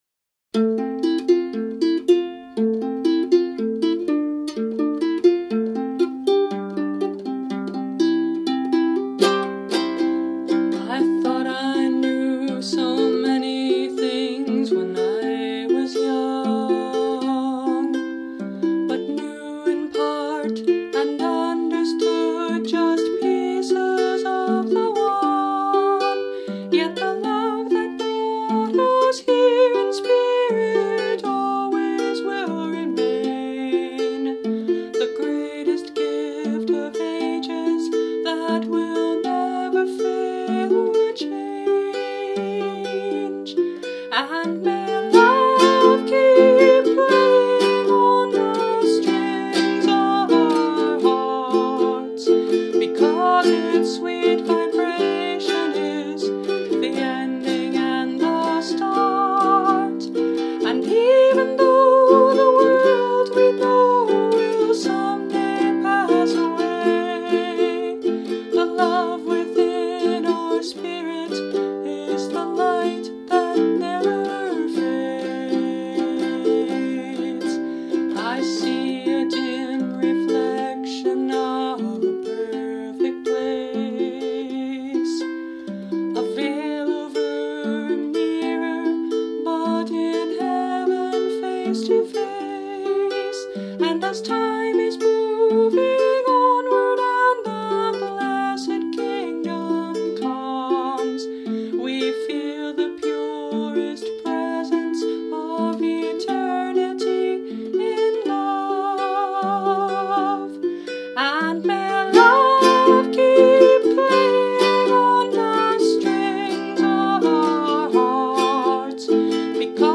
Instrument: Brio – Red Cedar Concert Ukulele